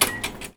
R - Foley 60.wav